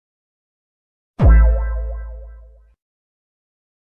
Rizz Sound Effect